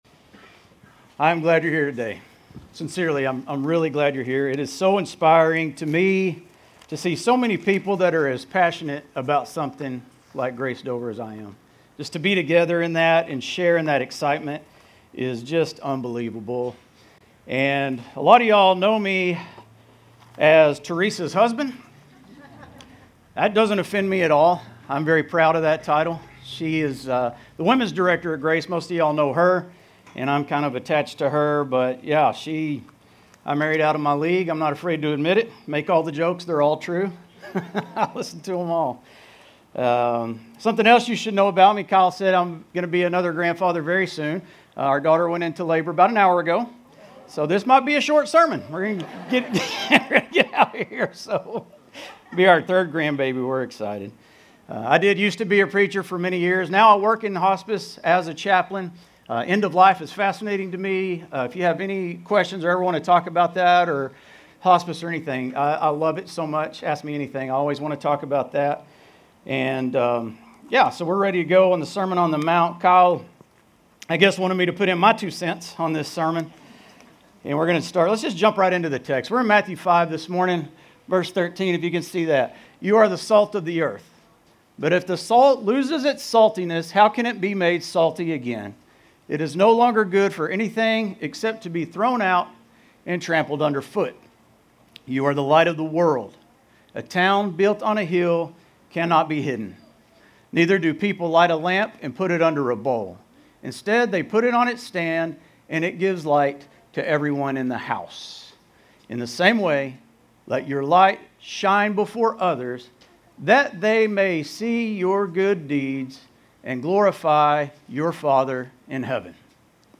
Grace Community Church Dover Campus Sermons 2_2 Dover Campus Feb 03 2025 | 00:25:47 1x 00:00 / 00:25:47 Subscribe Share RSS Feed Share Link Embed